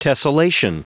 Prononciation du mot tessellation en anglais (fichier audio)
Prononciation du mot : tessellation